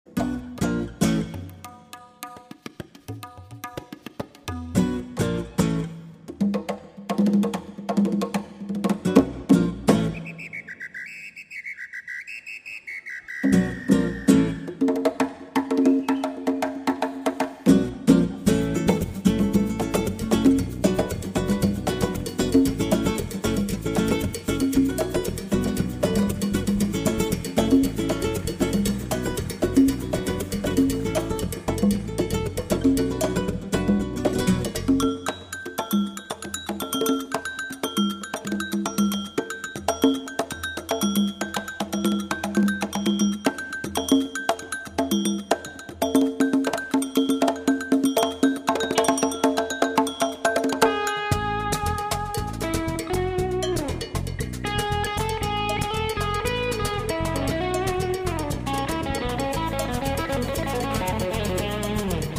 Recorded live to two-track
in San Antonio, Texas in October of 2004
Flamenco and Electric Guitars
Fretless Electric Bass Guitar
Congas and Cajon